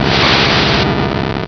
alakazam.aif